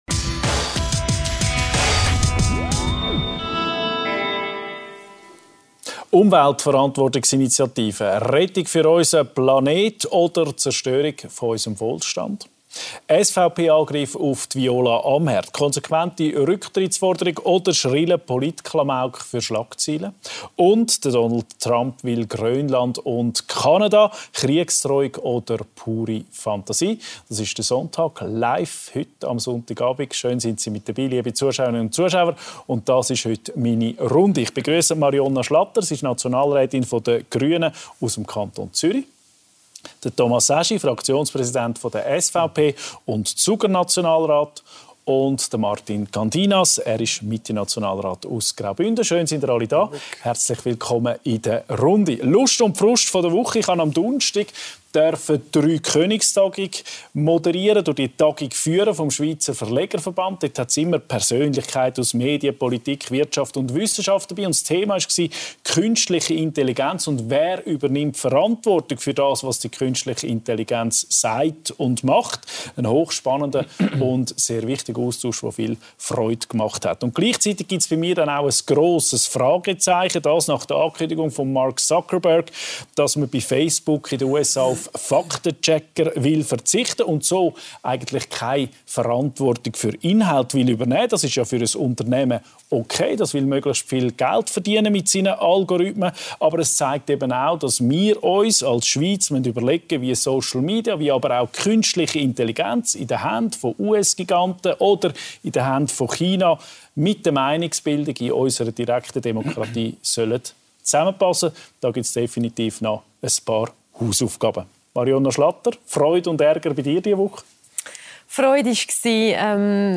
Gäste: • Thomas Aeschi, SVP-Fraktionspräsident, SVP-Nationalrat Kanton Zug • Martin Candinas, Mitte-Nationalrat Kanton Graubünden • Marionna Schlatter, Grüne-Nationalrätin Kanton Zürich